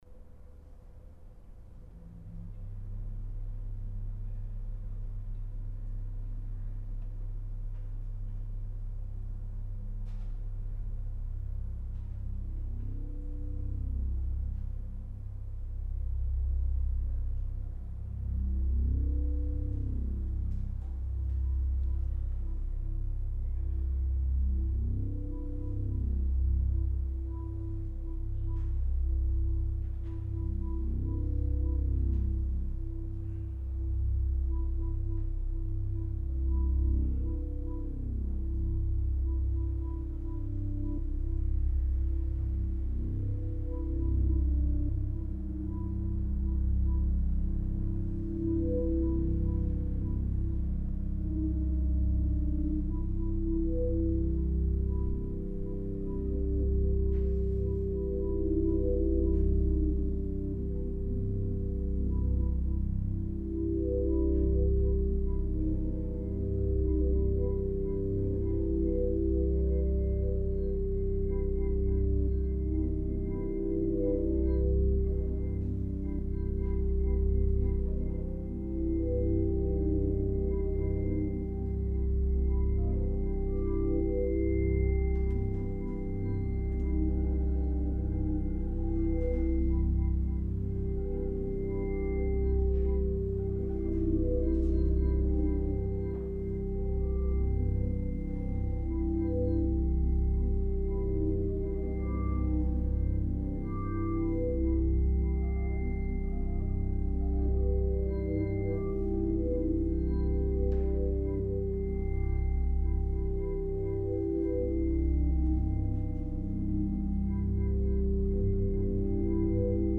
organ, piano, electronics, and recently tampura
improvisation for organ and electronics